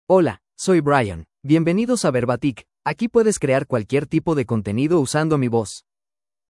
MaleSpanish (United States)
BrianMale Spanish AI voice
Brian is a male AI voice for Spanish (United States).
Voice sample
Brian delivers clear pronunciation with authentic United States Spanish intonation, making your content sound professionally produced.